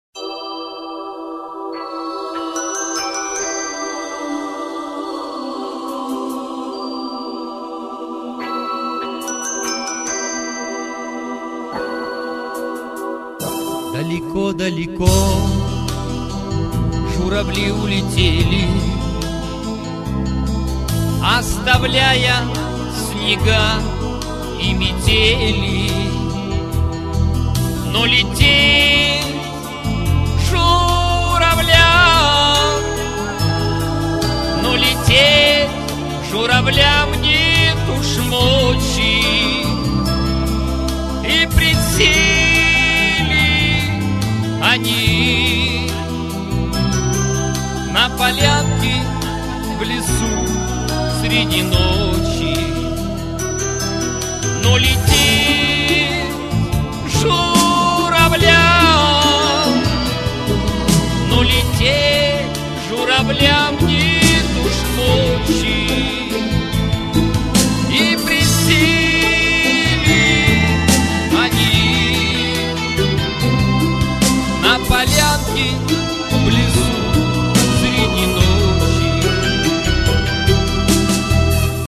Демо-версии наших песен